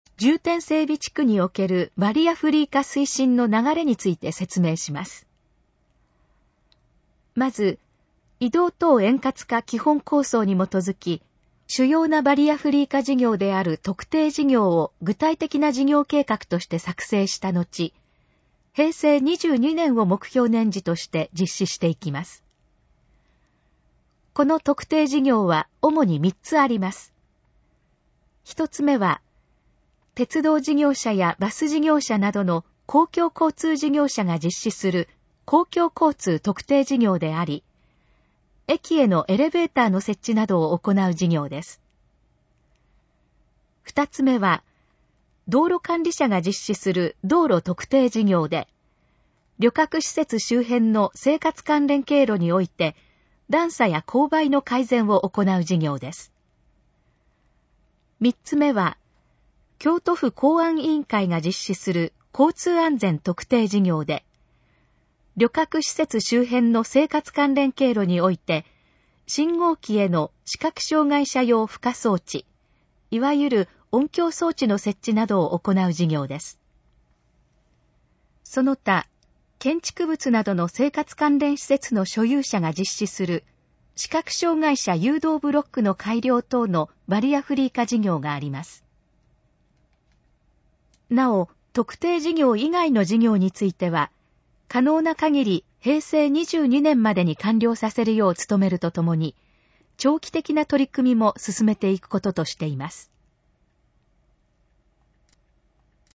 このページの要約を音声で読み上げます。
ナレーション再生 約419KB